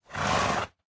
minecraft / sounds / mob / horse / idle1.ogg